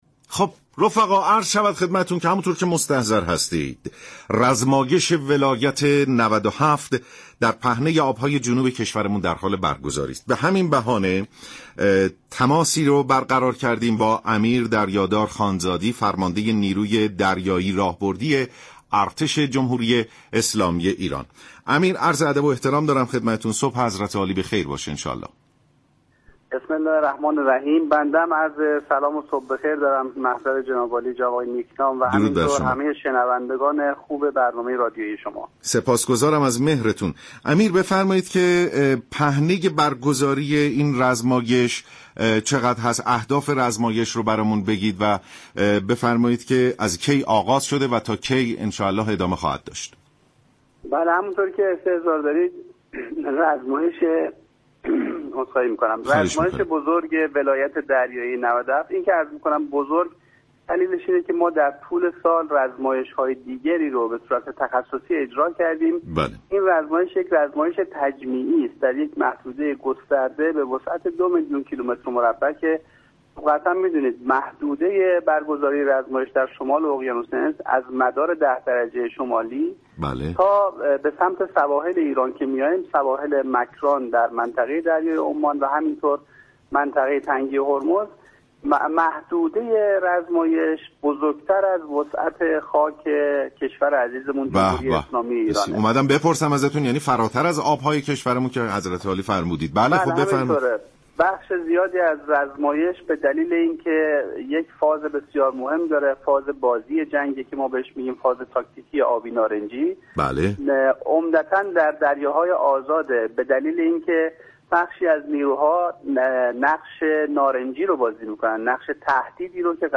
امیردریادار خانزادی فرمانده نیروی دریایی ارتش در برنامه سلام صبح بخیر رادیو ایران گفت : دو ناوشكن بومی ایرانی به نام سهند و فاتح در این رزمایش حضور دارند